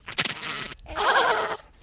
Index of /90_sSampleCDs/Zero-G - Total Drum Bass/Instruments - 3/track61 (Vox EFX)
07-Sinister Baby.wav